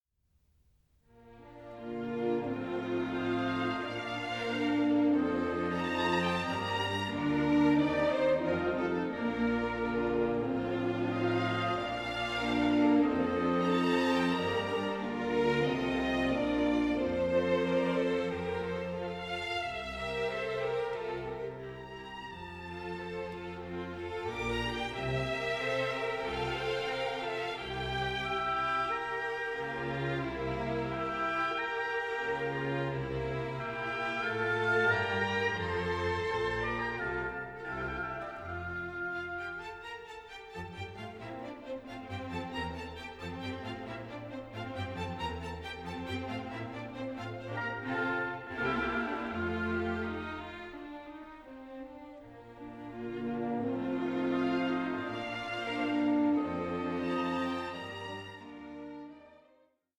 alternative slow movement